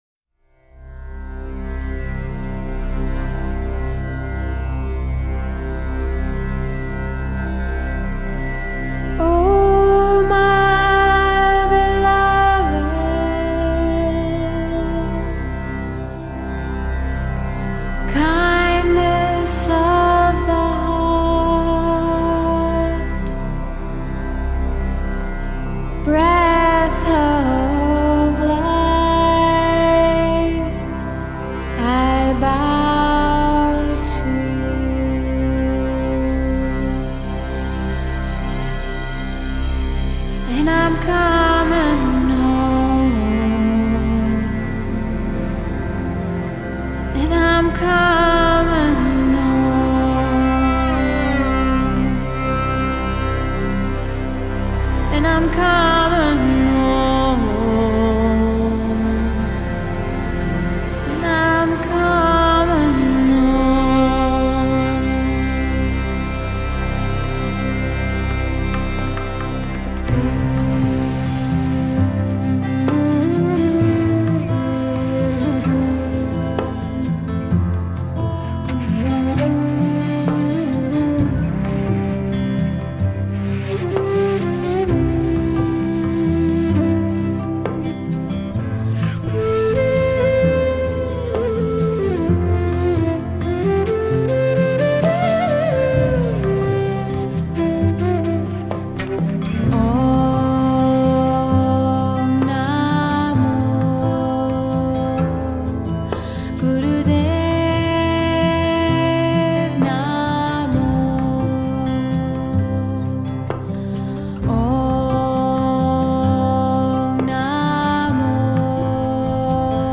ESCUCHAR MANTRA